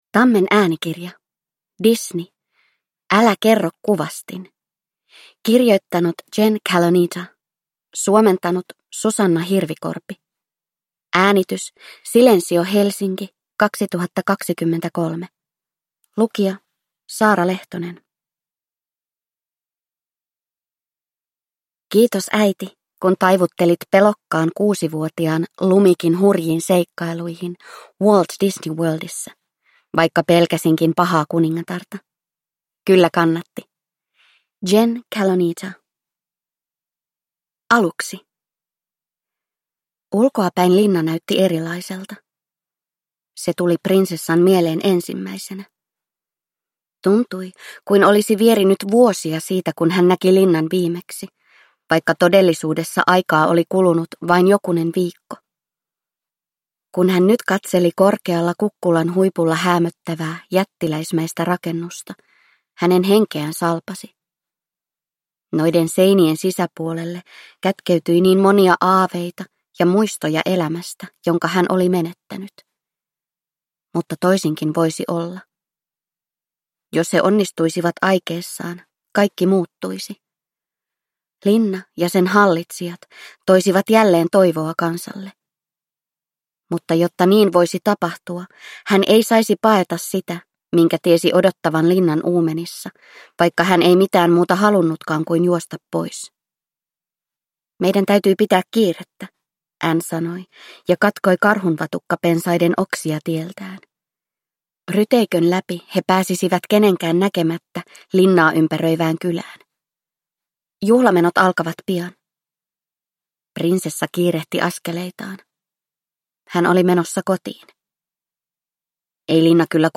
Älä kerro, kuvastin. Twisted Tales (ljudbok) av Disney | Bokon